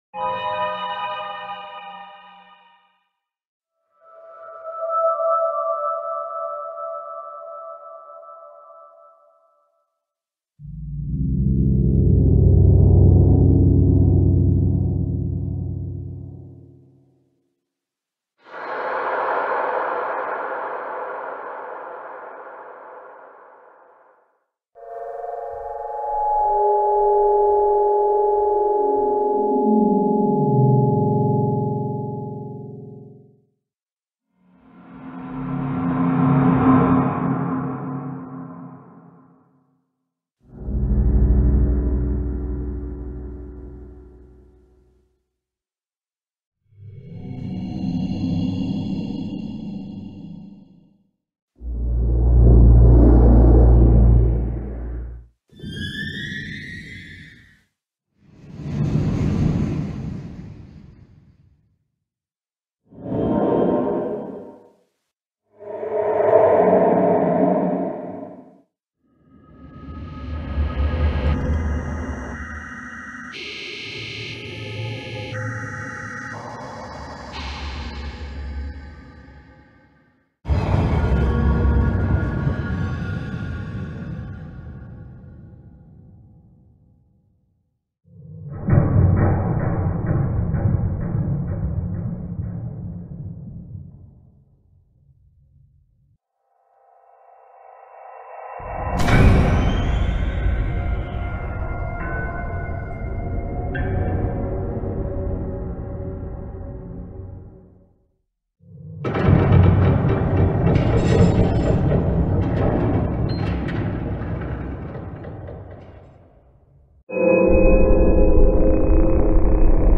Звуки пещер из Майнкрафт
Находясь в пещерах Майнкрафт можно услышать множество разных тревожных и порой даже пугающих звуков.
Все существующие звуки пещер